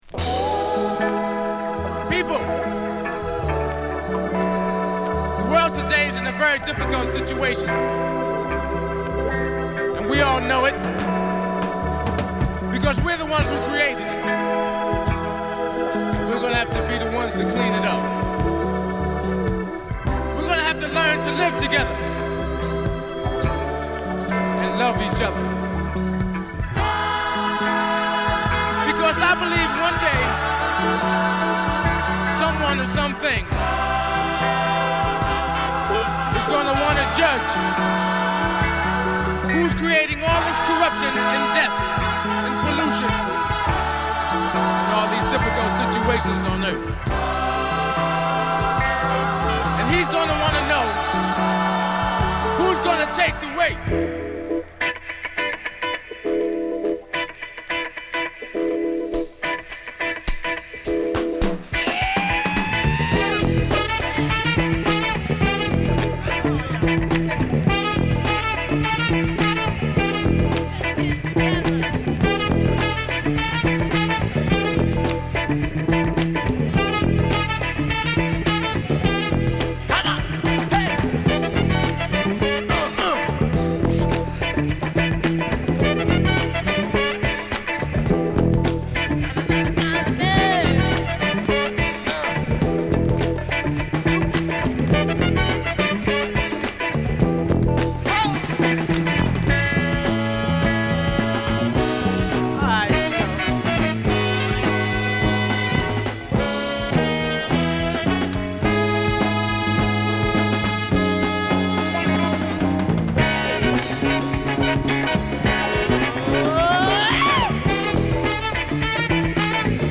メロウで神々しいイントロから、彼ららしいファンクチューンに展開する名曲！！
ライブ感もナイスなPart 2もカッコ良いです！